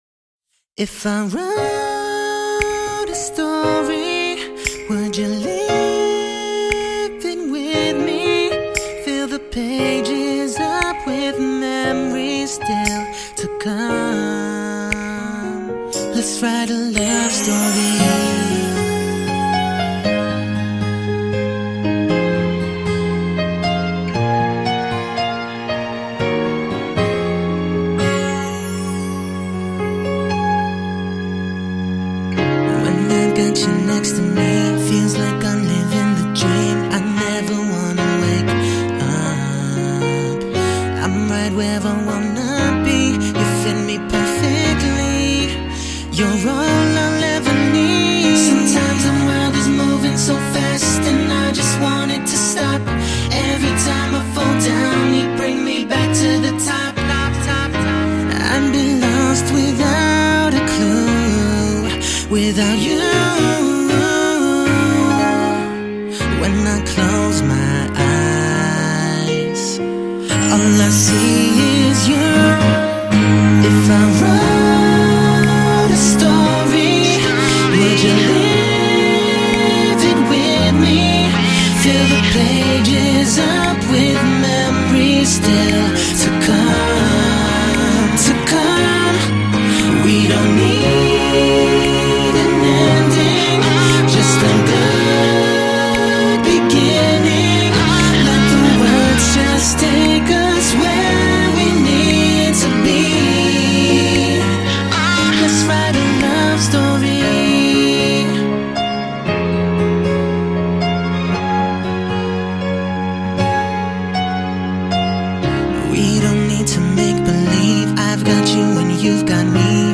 他的声音清澈细腻，颇有女声味道。